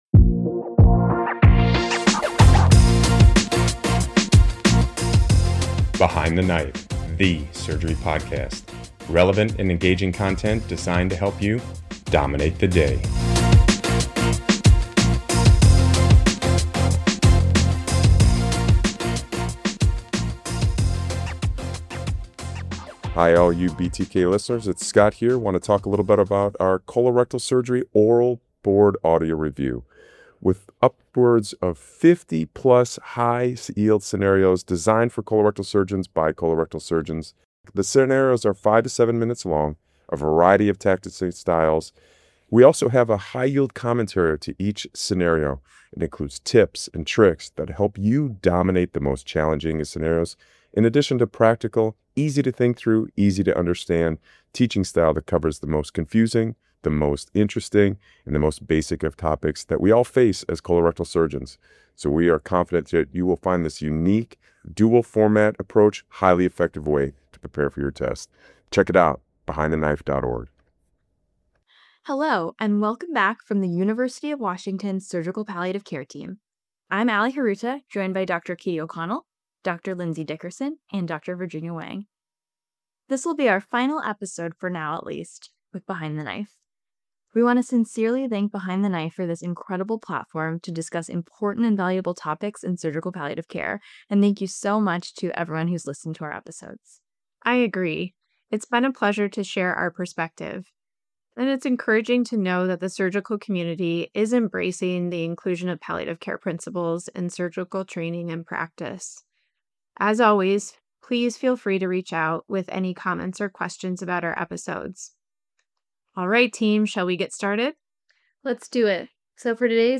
Join the University of Washington Surgical Palliative Care Team for their final episode of this series — a dual journal review and clinical challenges discussion on assessing medical decision-making capacity.